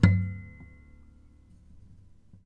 strings_harmonics
harmonic-11.wav